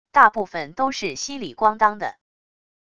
大部分都是稀里咣当的wav音频